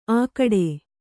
♪ ākaḍe